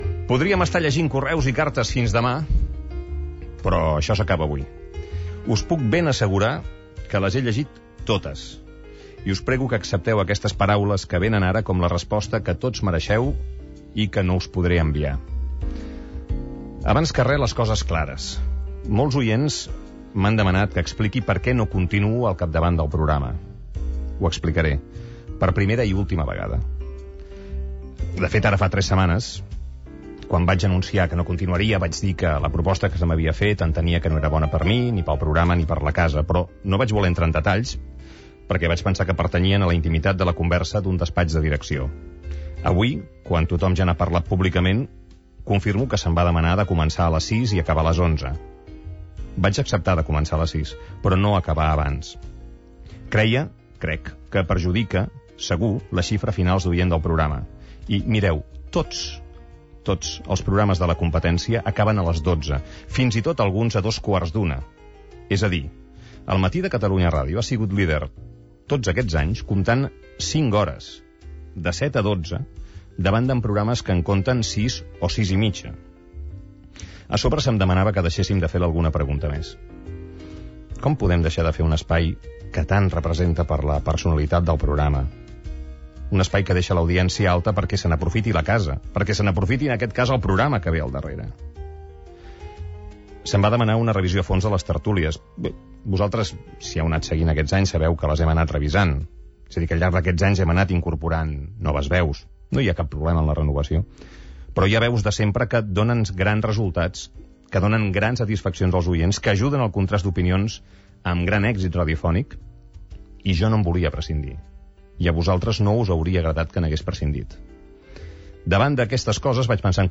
Ultima edició del programa presentada per Antoni Bassas,
Final de l'última hora amb les paraules de comiat d'Antoni Bassas.
Info-entreteniment